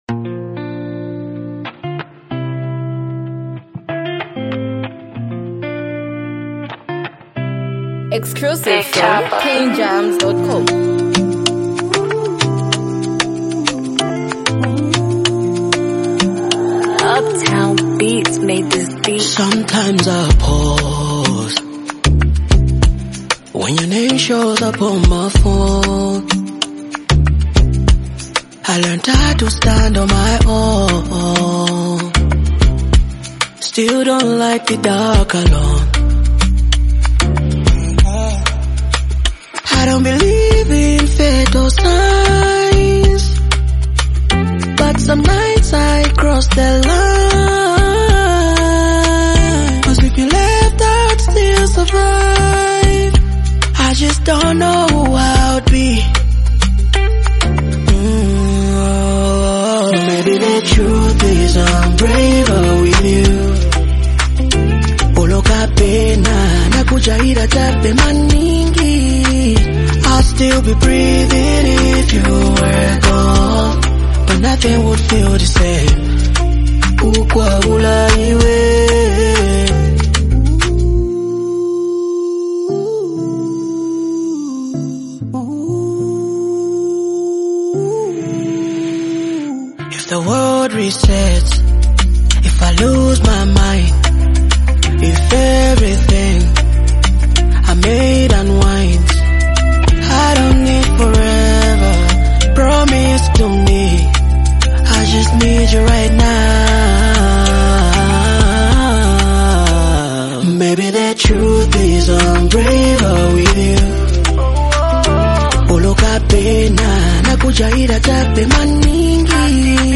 deeply emotional love song
smooth Afro-fusion sounds
soft and expressive vocals
powerful love ballad